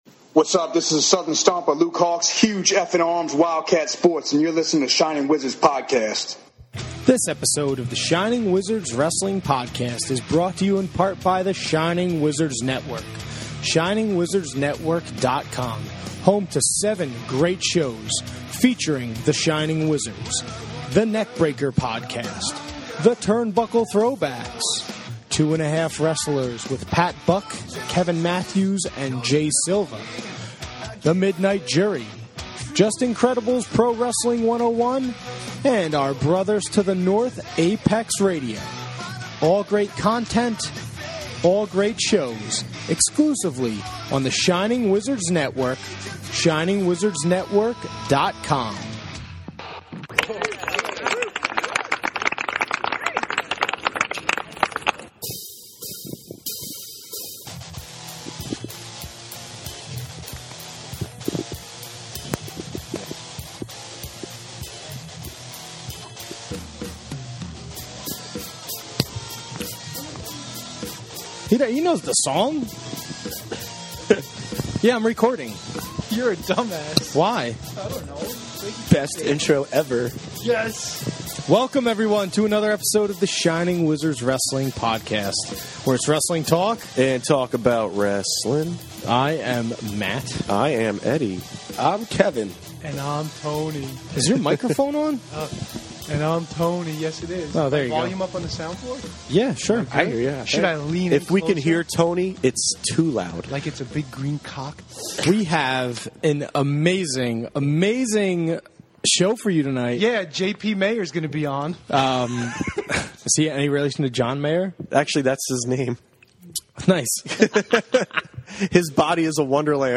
The Boys are in Studio B. They get to talk to Fozzy Frontman Chris Jericho about his upcoming shows in the NY/NJ Area. Then the boys cover all things wrestling, rib some fans, call into another podcast, and have some laughs.